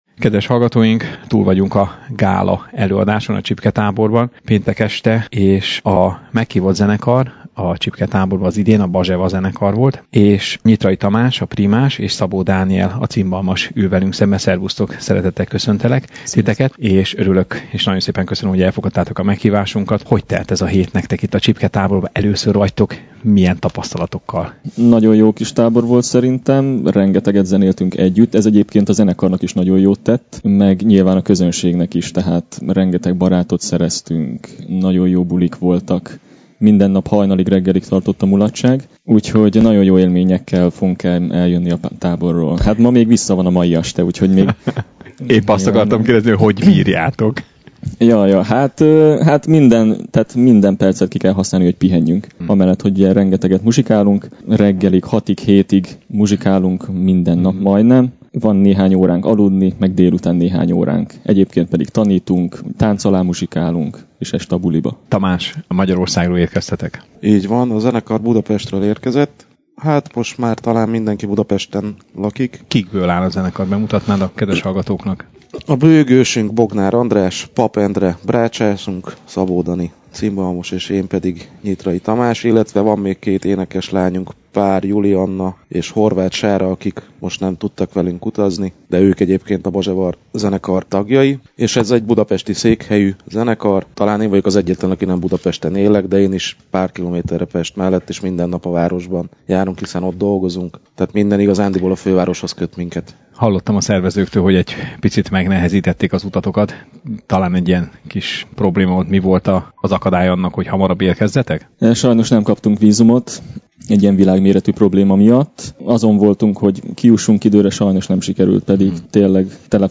majd a gála est utáni interjúban többek között erről  is kérdeztem